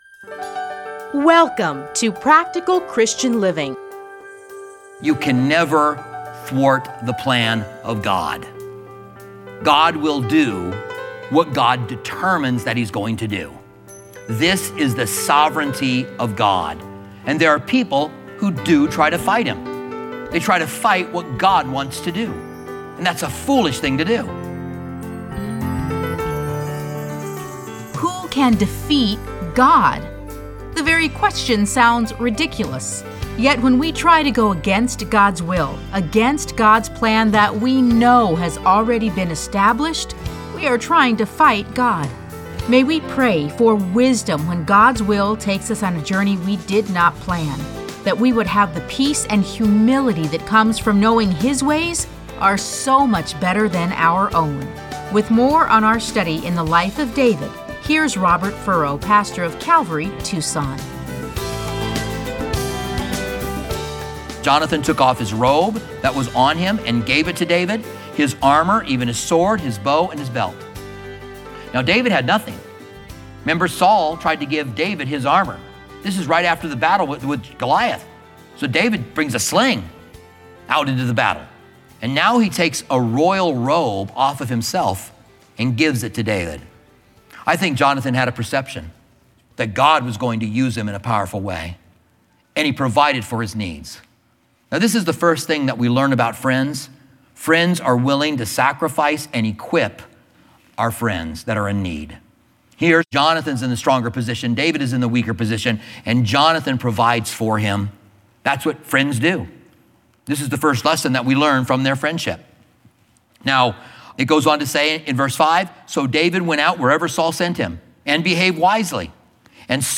Listen to a teaching from 1 Samuel 14:1-46.